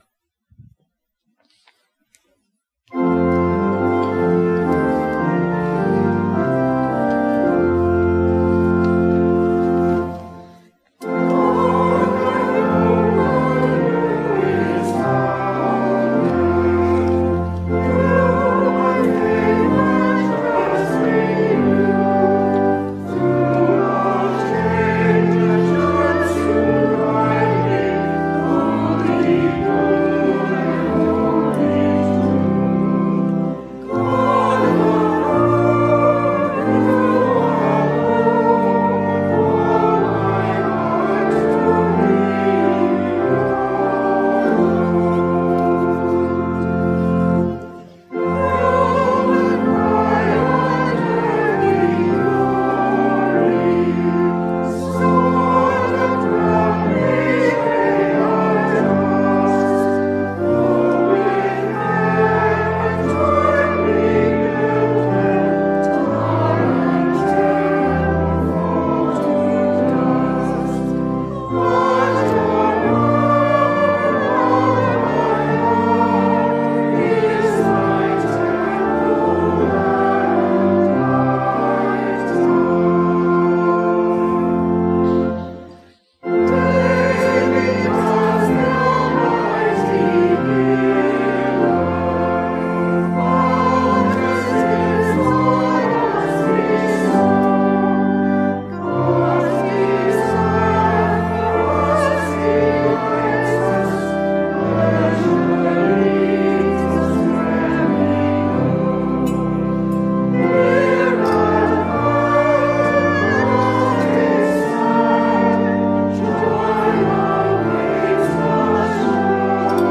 WORSHIP - 10:30 a.m. Twenty-sixth Sunday after Pentecost